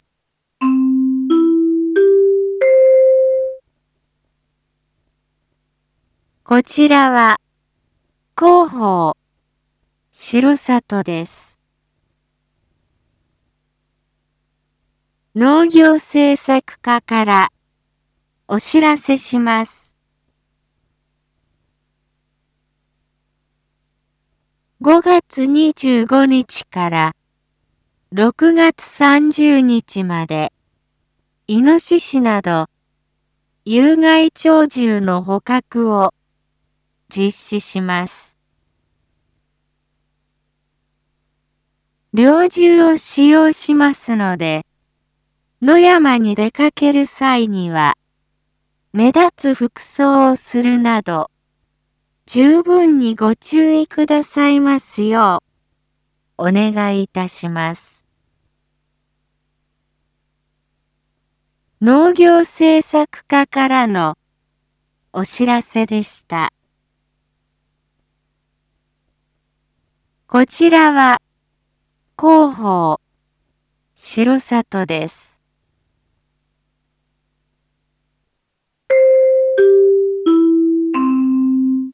一般放送情報
Back Home 一般放送情報 音声放送 再生 一般放送情報 登録日時：2025-06-22 19:01:23 タイトル：有害鳥獣捕獲（４） インフォメーション：こちらは、広報しろさとです。